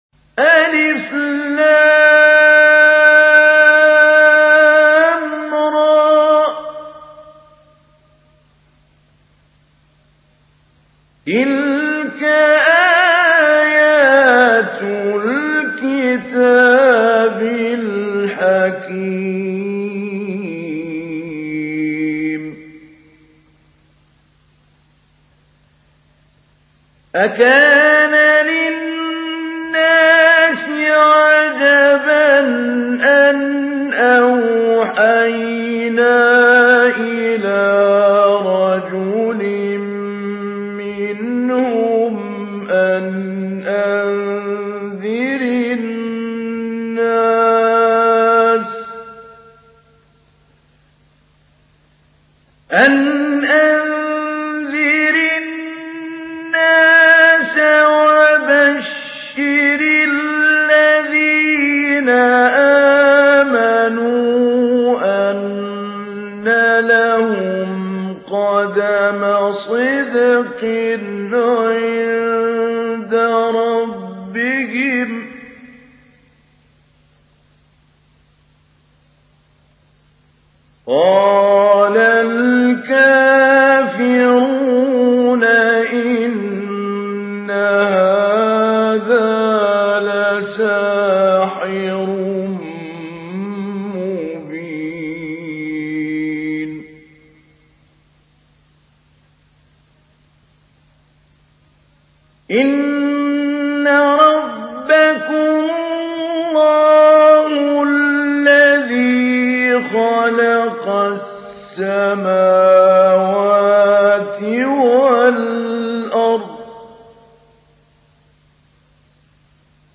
سورة يونس | القارئ محمود علي البنا